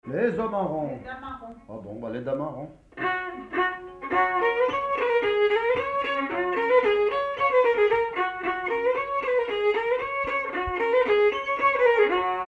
Mazurka partie 1
danse : mazurka
circonstance : bal, dancerie
Pièce musicale inédite